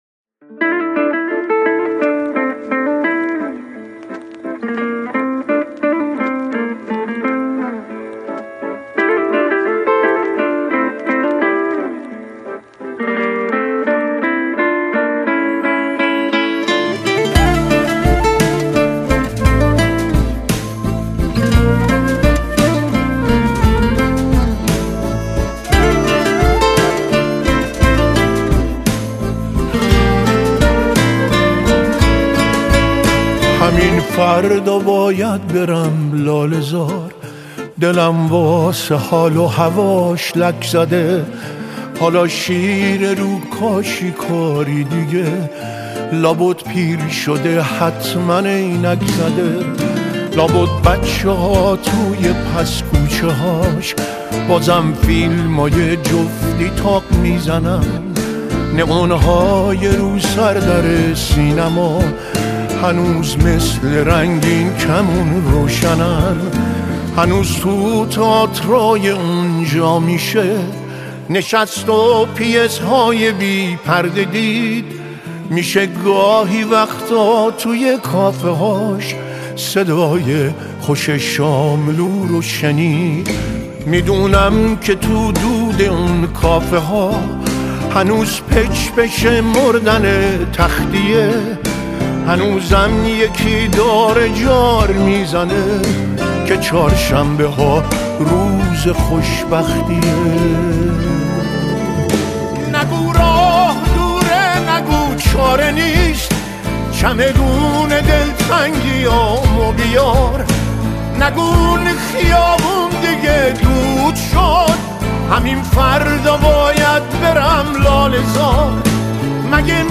آهنگ غمگین
آهنگ نوستالژی